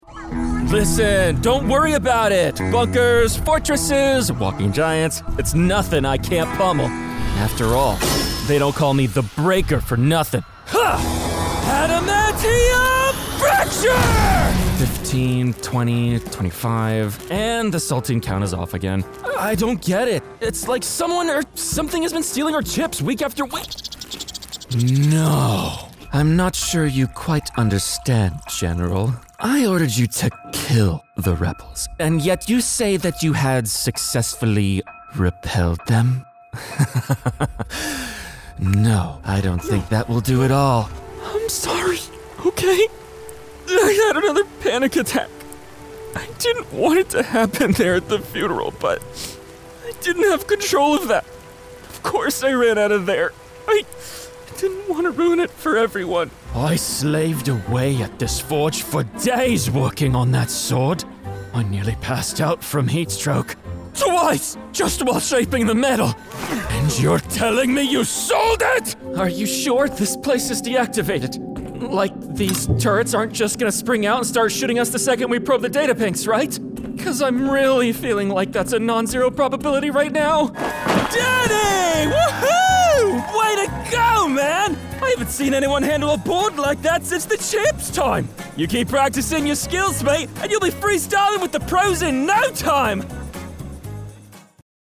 Teenager, Young Adult, Adult, Mature Adult
Has Own Studio
ANIMATION 🎬
teenager
villain/overlord
warrior/fighter